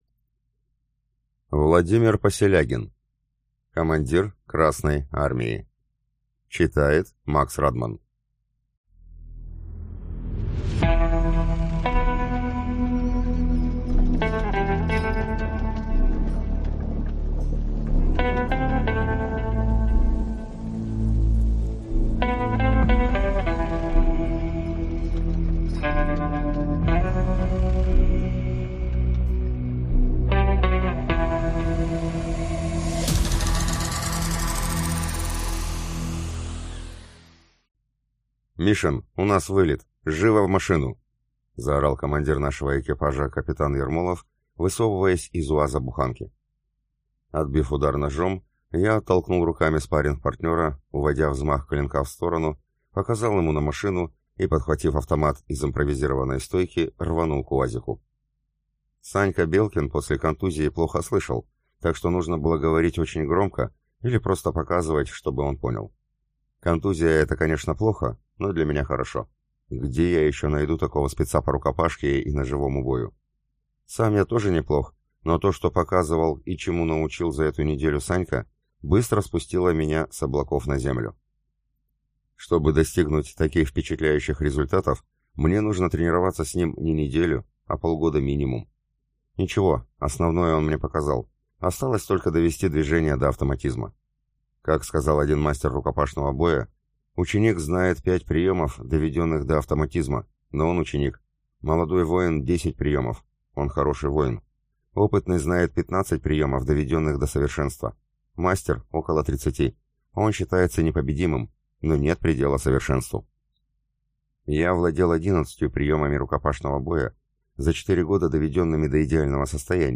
Аудиокнига Командир Красной Армии: Командир Красной Армии.